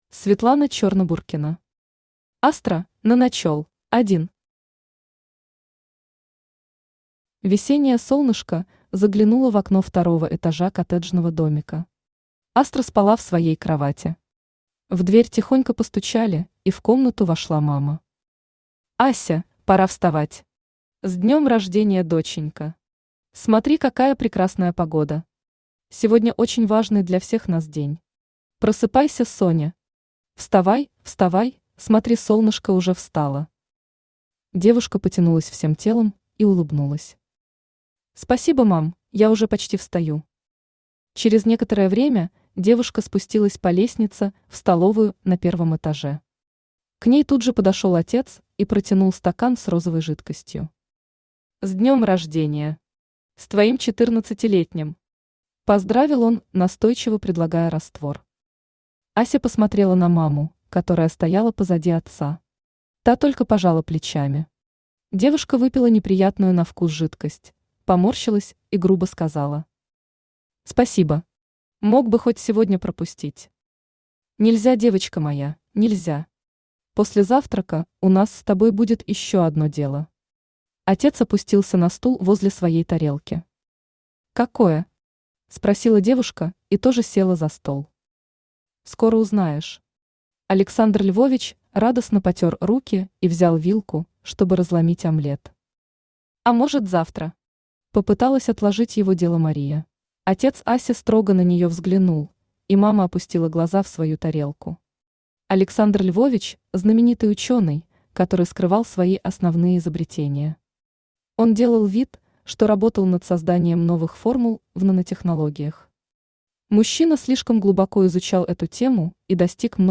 Aудиокнига Астра – наночел Автор Светлана Чернобуркина Читает аудиокнигу Авточтец ЛитРес.